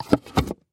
Звук подарка: Крышка легла на коробку